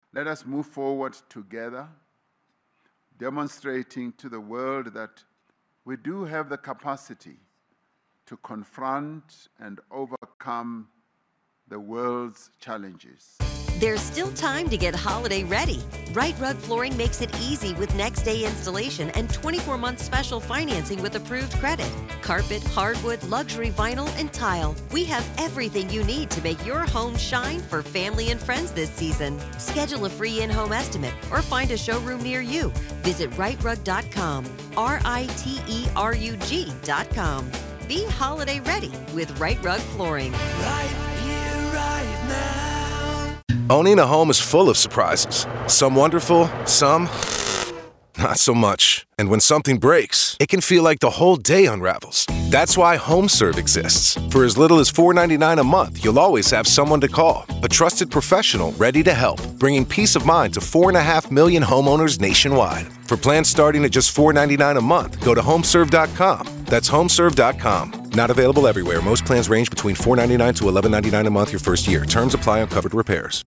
At the G20 summit in Johannesburg, South Africa, host and South African President Cyril Ramaphosa closes the two-day meeting.